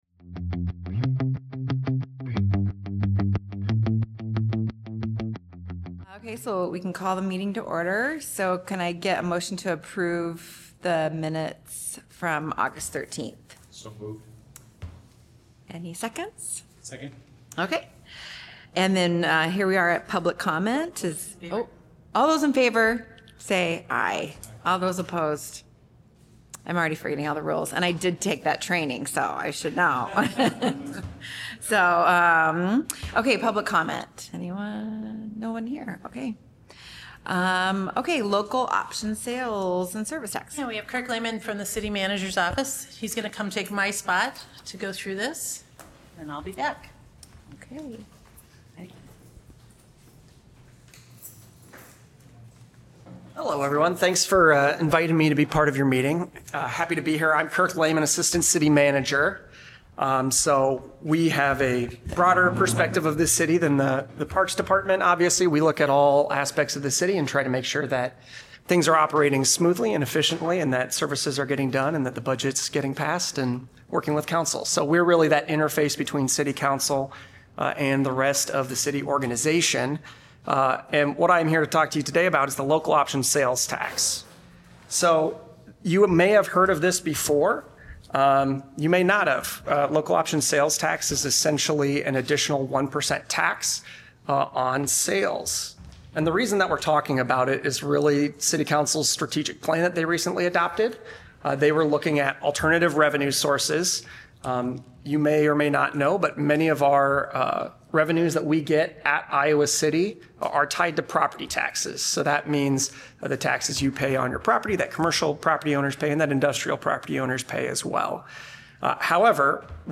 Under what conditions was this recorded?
A meeting of the City of Iowa City's Parks and Recreation Commission.